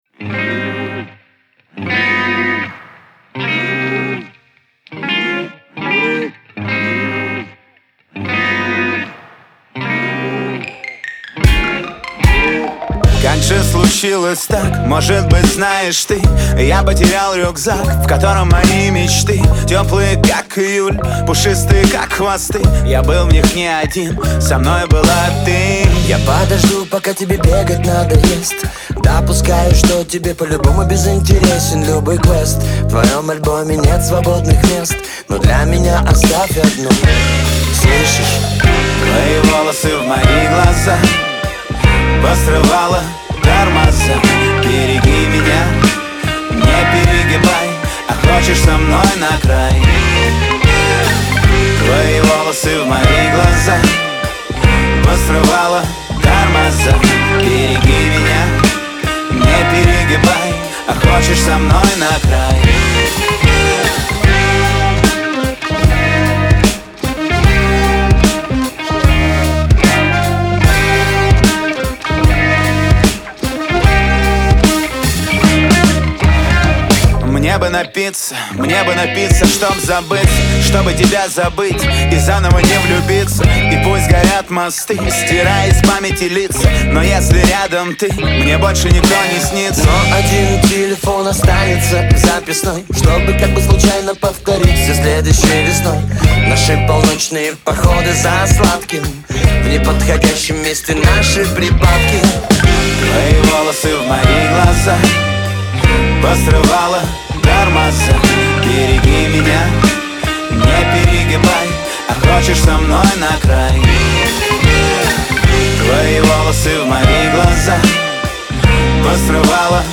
романтичный поп-трек с легким и мелодичным звучанием